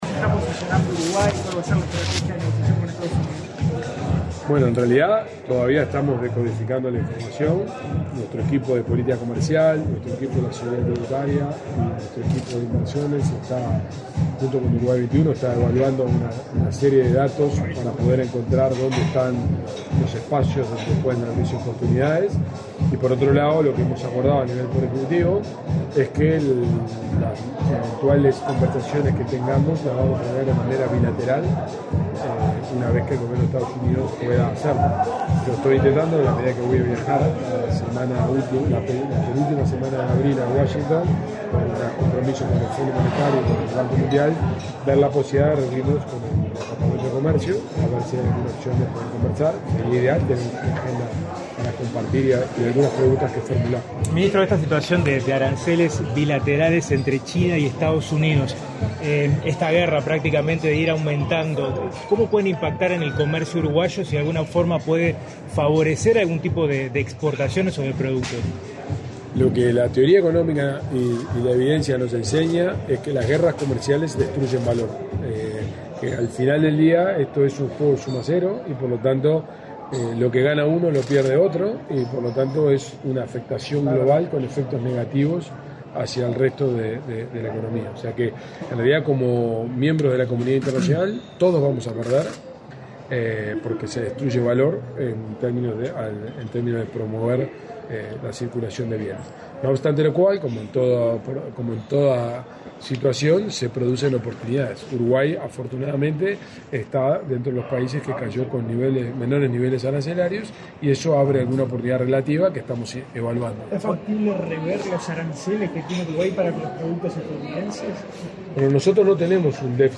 Declaraciones del ministro de Economía, Gabriel Oddone 08/04/2025 Compartir Facebook X Copiar enlace WhatsApp LinkedIn Este martes 8 en Montevideo, el ministro de Economía y Finanzas, Gabriel Oddone, fue entrevistado por medios de prensa, luego de disertar en el almuerzo de trabajo de la Asociación de Dirigentes de Marketing.